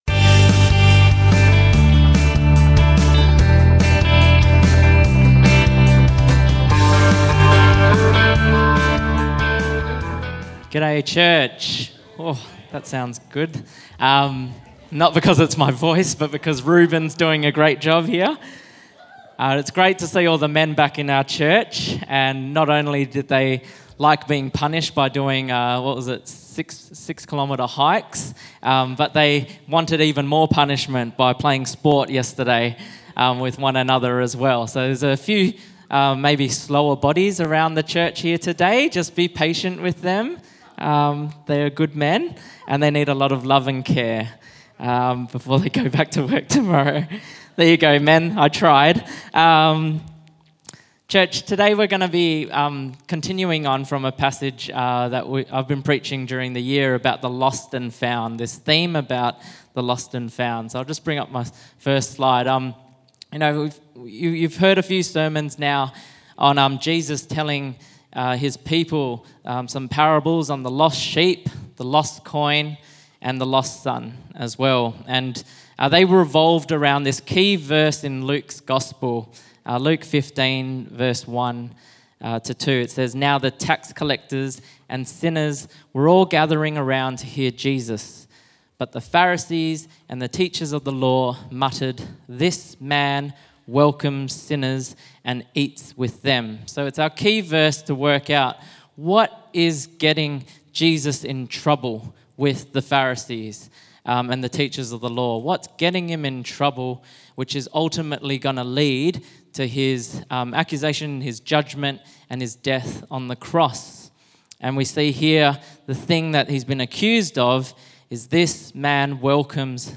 Sermons | Firstlight Church